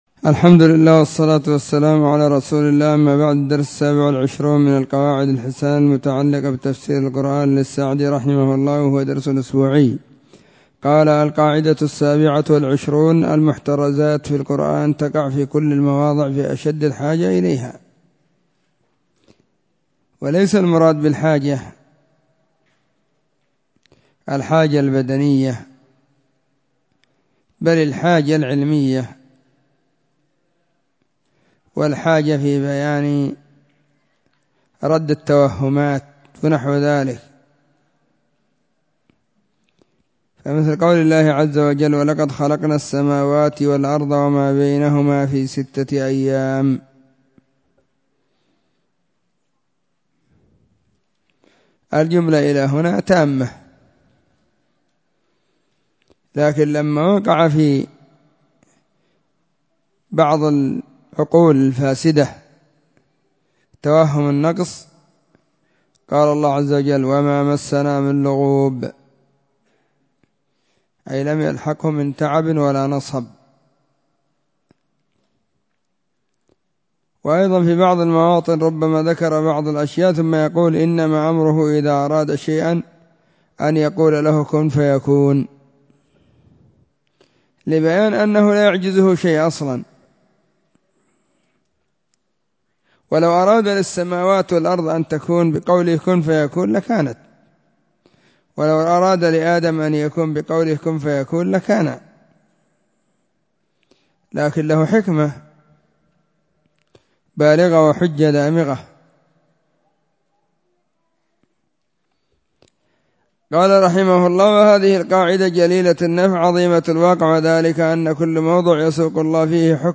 🕐 [بعد صلاة الظهر في كل يوم الخميس]
📢 مسجد الصحابة – بالغيضة – المهرة، اليمن حرسها الله.